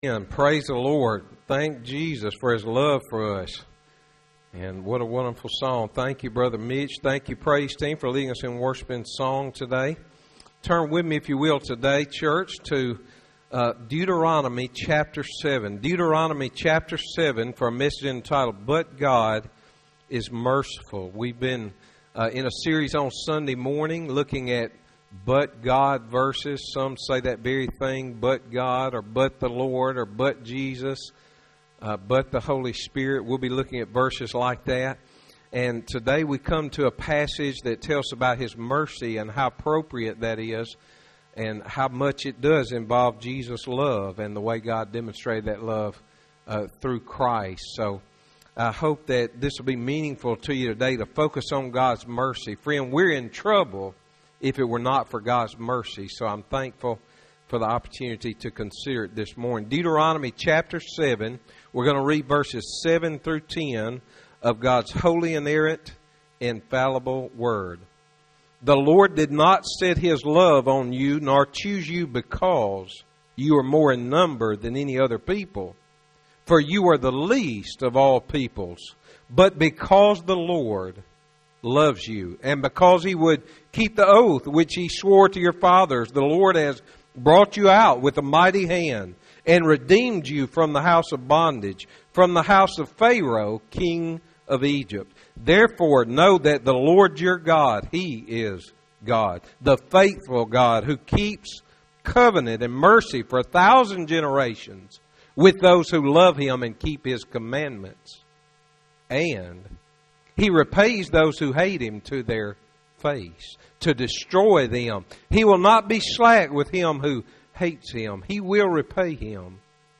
Sermons - Highland Baptist Church